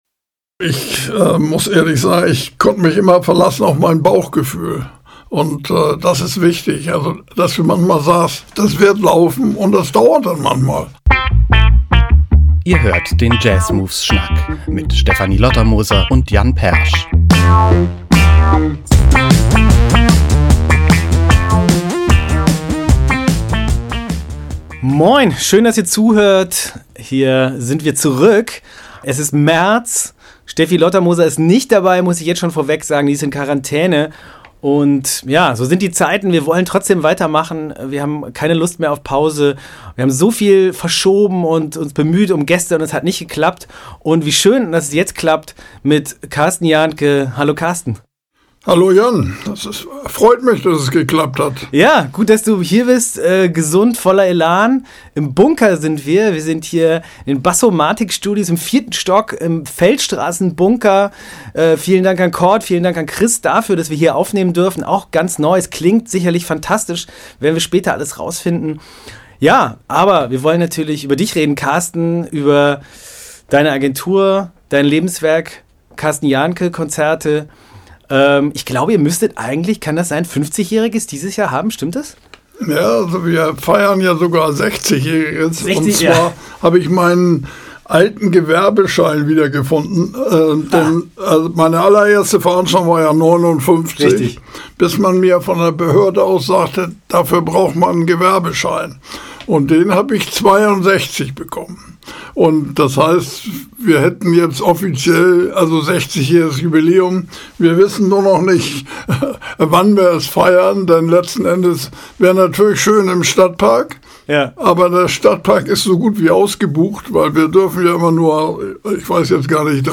In unserer ersten Folge aus dem Studio Bassomatic